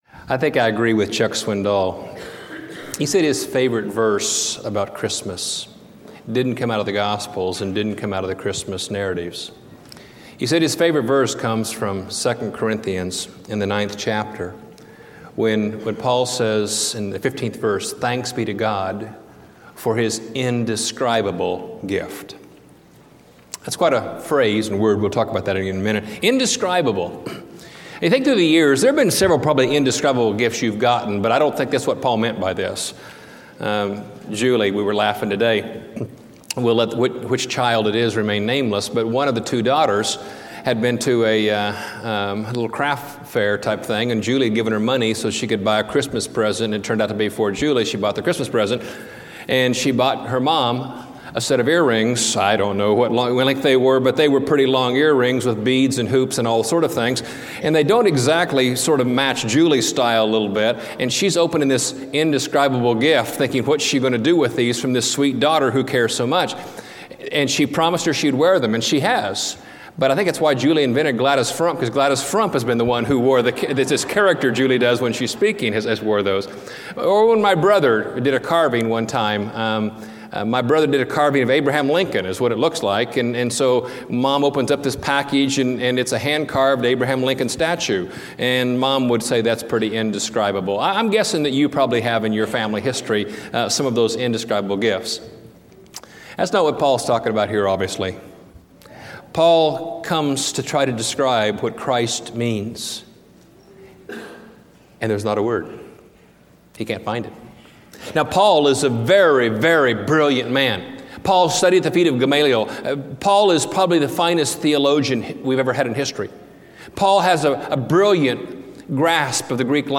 Praise God for His Indescribable Gift (Christmas Eve 2005) Preached at College Heights Christian Church, Christmas Eve Service December 24, 2005 Series: Scripture: 2 Corinthians 9:15 Audio